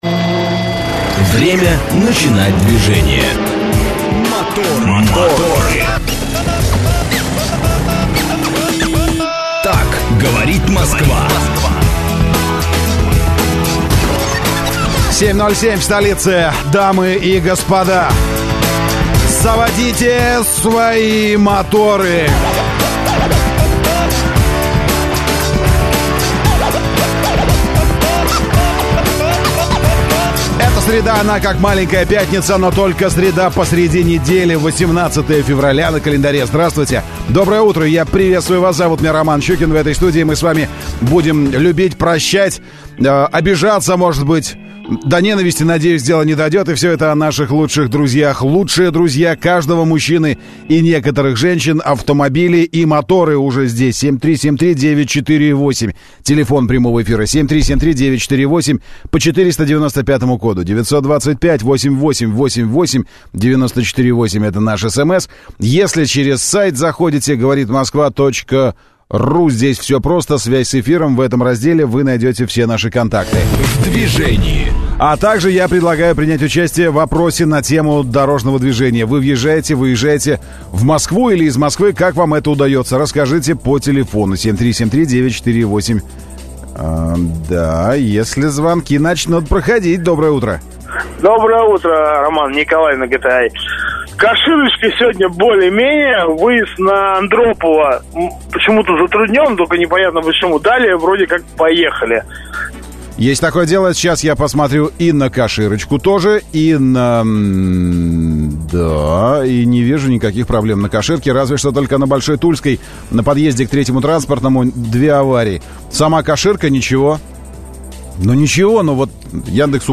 Утренняя программа для водителей и не только. Ведущие рассказывают о последних новостях автомобильного мира, проводят со слушателями интерактивные «краш-тесты» между популярными моделями одного класса, делятся впечатлениями от очередного тест-драйва.